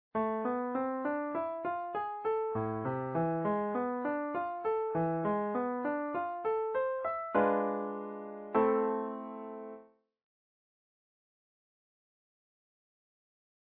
diminished 7th
Aurally examine the distillation of these pitches from 'A' harmonic minor.